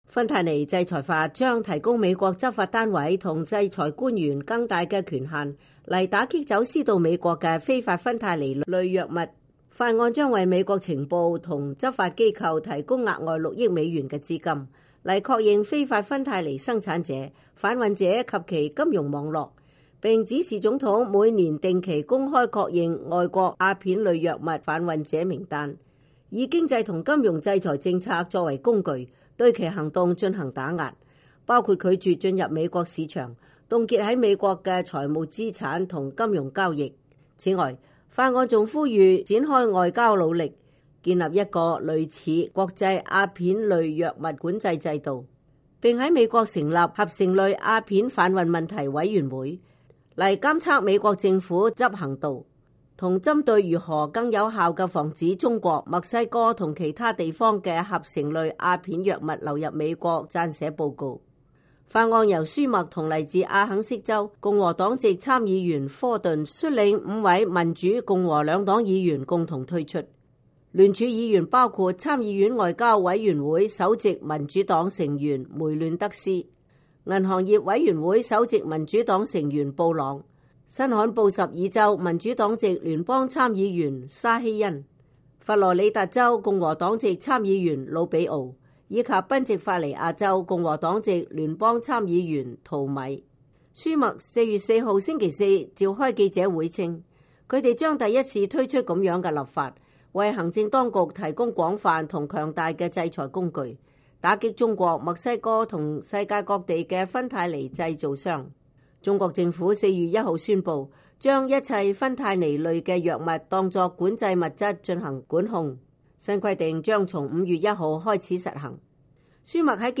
參議院少數黨領袖舒默（Sen. Chuck Schumer, D-NY）2019年4月4日召開記者會，推出《芬太尼制裁法》。
在中國各項議題上採取強硬立場的魯比奧參議員在記者會上對於中國政府提出的新規定表示，樂見中國採取行動，但美國必須再三查實中國是否有切實執行政策。